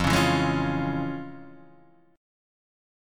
FmM7#5 Chord